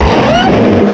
cry_not_garchomp.aif